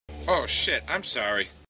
hitsound
hitsound_TwbGTJx.mp3